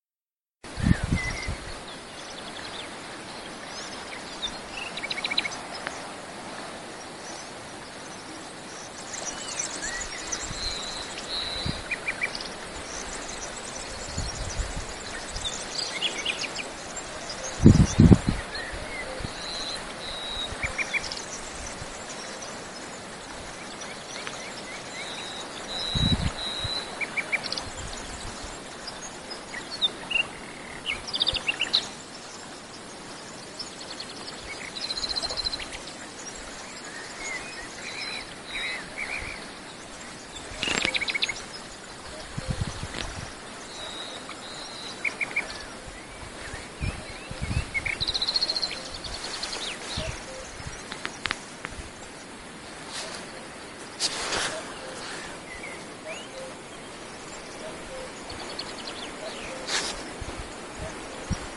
Grabación realizada en Hoyo Redondo, Gredos, Ávila.
Se distinguen diferentes sonidos del canto de los pájaros. ¿Habéis escuchado al cuco?